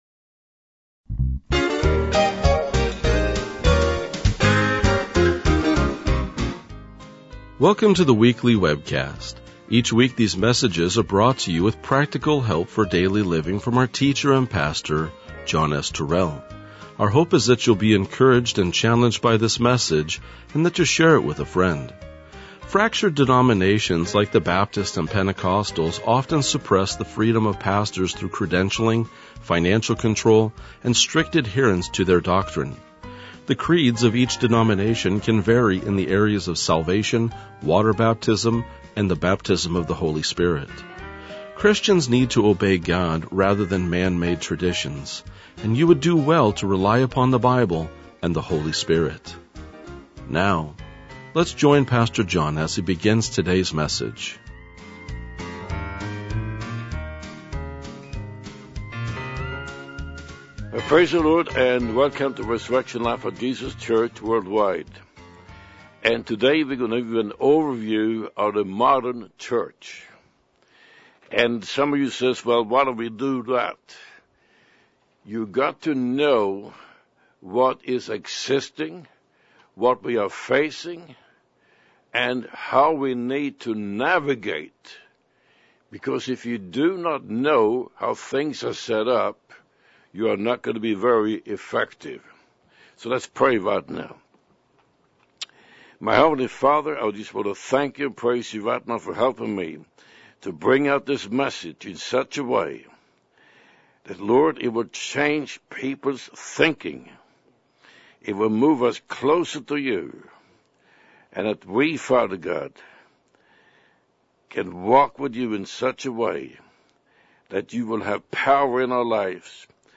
RLJ-2033-Sermon.mp3